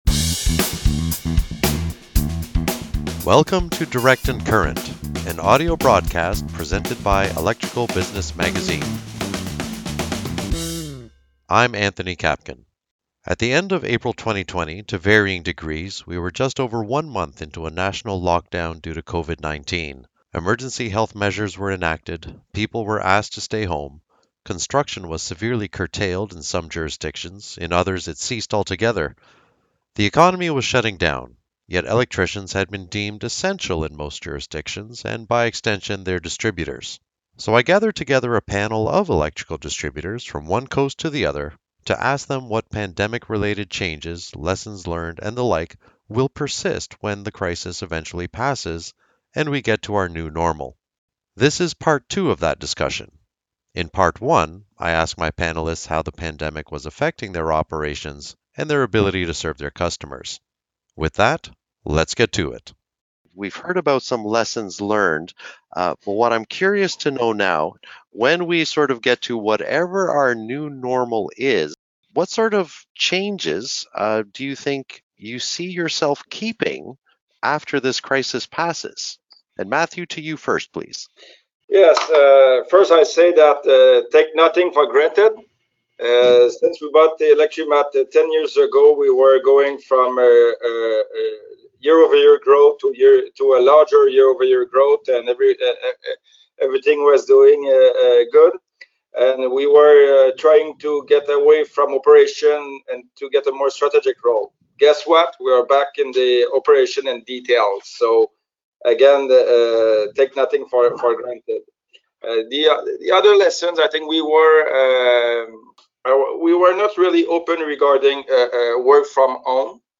Pandemic roundtable with electrical distributors, Part 2
The economy was shutting down, yet electricians had been deemed “essential” in most jurisdictions and, by extension, their distributors. So I gathered together a panel of electrical distributors, from one coast to the other, to learn how the pandemic was affecting their operations and their ability to serve their customers.